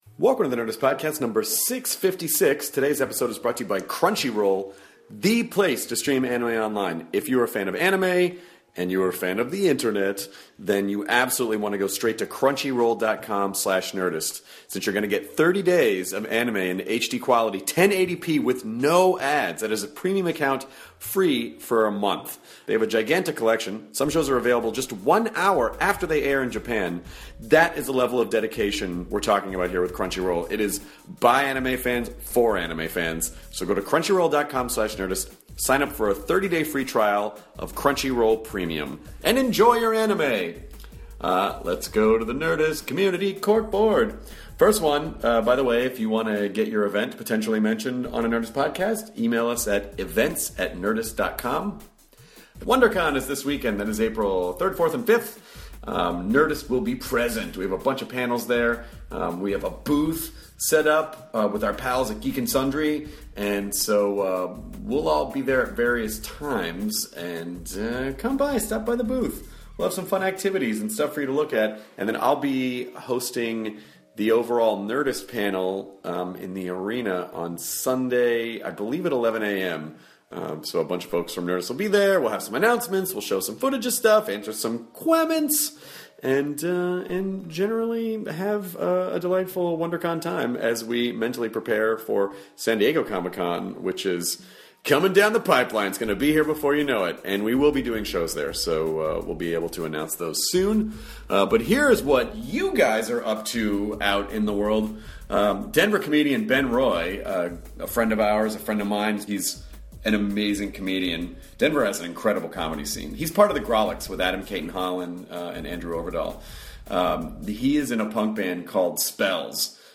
They also talked about Death Cab for Cutie’s new album Kintsugi, their upcoming tour and Ben plays a song live!